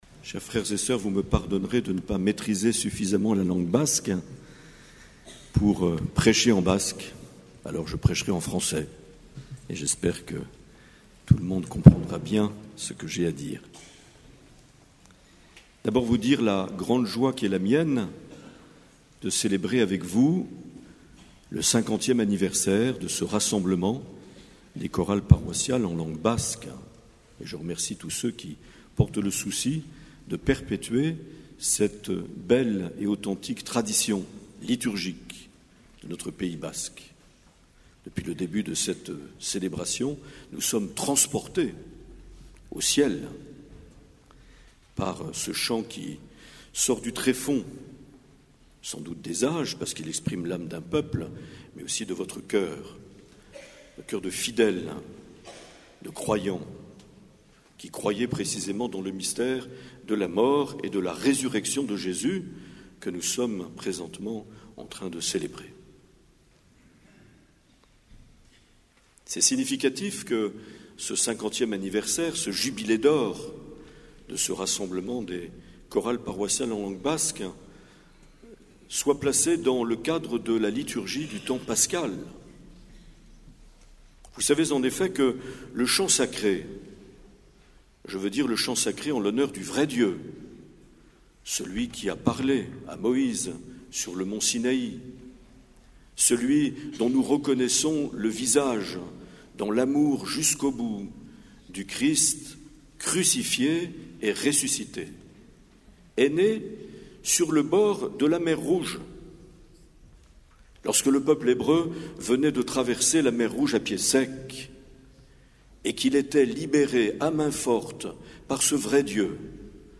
Accueil \ Emissions \ Vie de l’Eglise \ Evêque \ Les Homélies \ 5 mai 2019 - Hasparren - Rassemblement Kanta Jaunari.
Une émission présentée par Monseigneur Marc Aillet